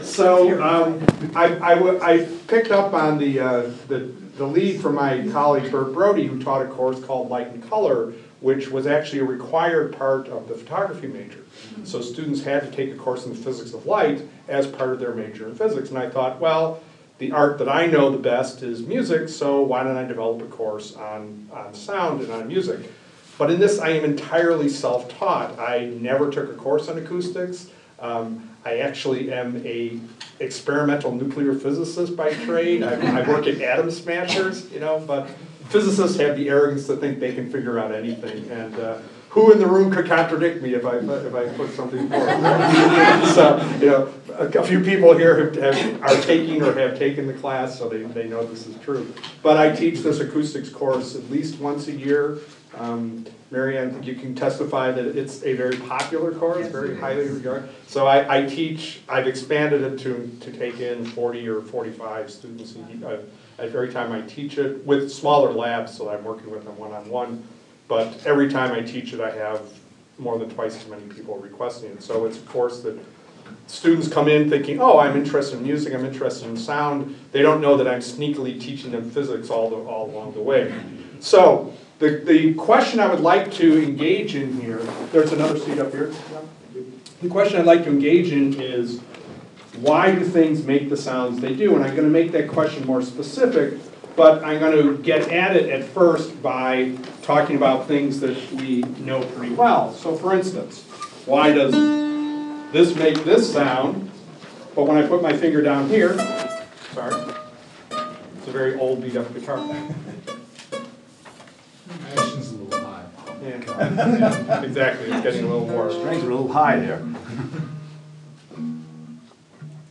A special broadcast from " events Sound in Theory, Sound in Practice : Apr 07, 2016 - Apr 08, 2016 Bard College